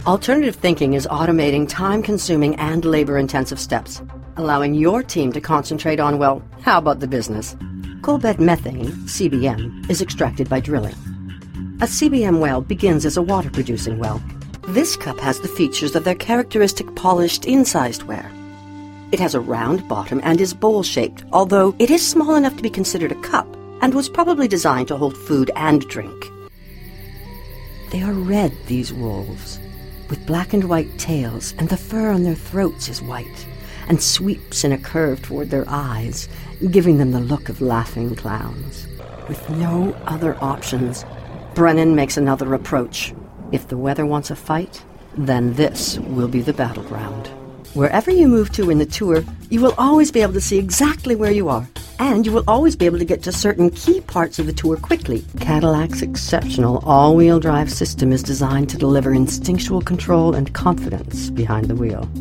rich, warm, smooth, soothing, calming, sultry, confident, and comforting
Sprechprobe: Industrie (Muttersprache):